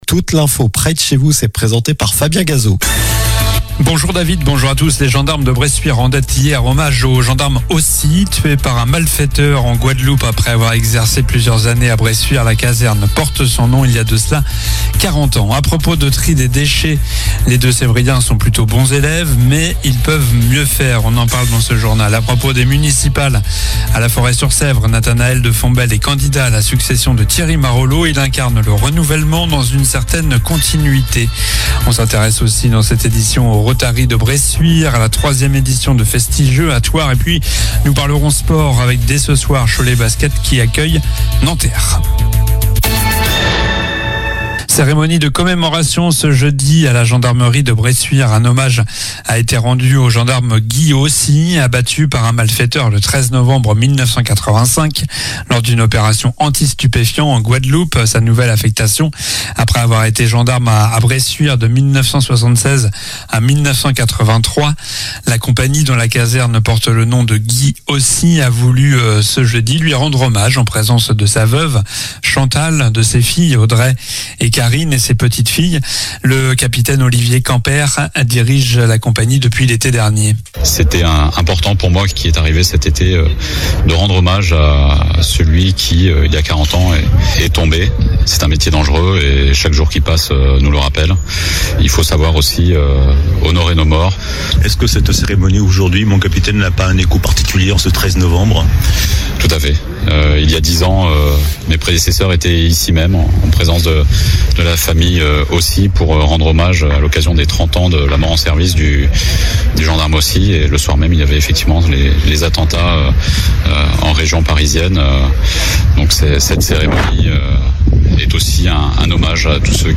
Journal du vendredi 14 novembre (midi)